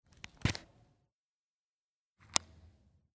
Akkuschrauber IXO 6
Bit entfernen und einstecken
58958_Bit_entfernen_und_einstecken.mp3